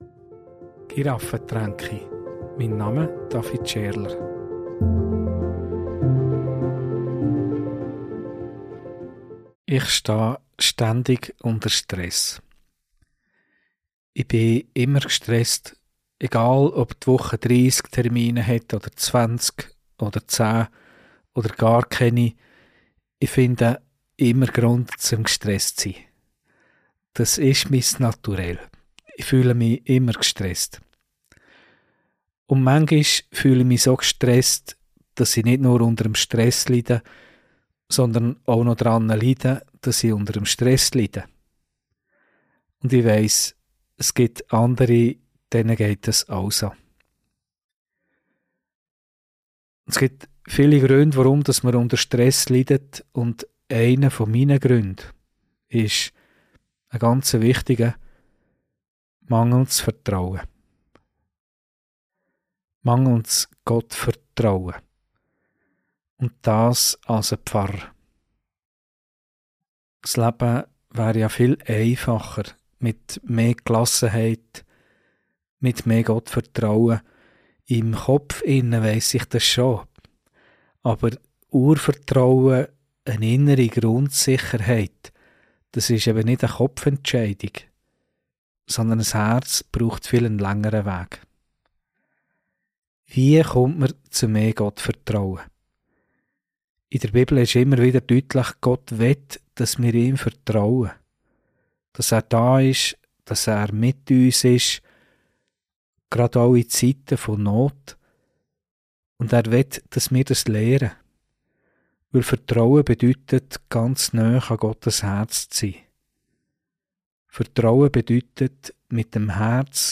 Predigt - Mit Isaak Urvertrauen schöpfen ~ Giraffentränke Podcast